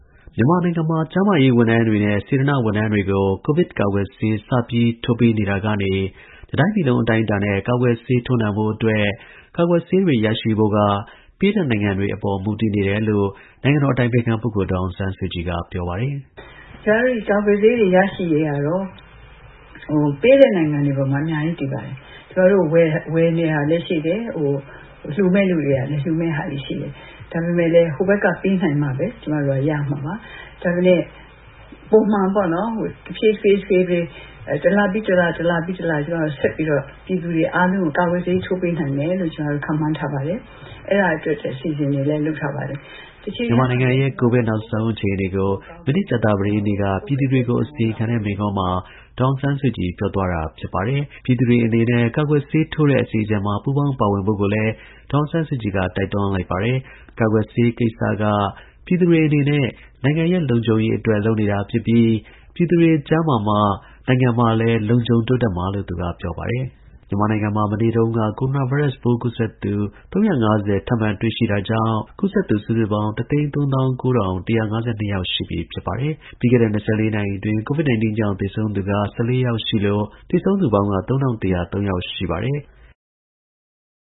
ကိုဗစ် နောက်ဆုံးအခြေအနေကို မနေ့ ကြာသပတေးနေ့က ပြည်သူတွေကို အစီရင်ခံတဲ့ မိန့်ခွန်းမှာ ဒေါ်အောင်ဆန်းစုကြည် ပြောသွားတာ ဖြစ်ပါတယ်။